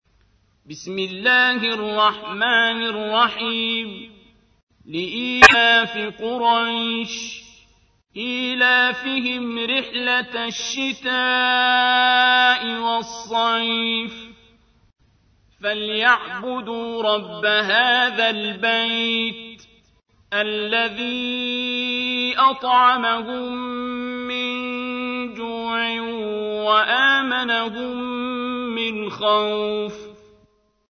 تحميل : 106. سورة قريش / القارئ عبد الباسط عبد الصمد / القرآن الكريم / موقع يا حسين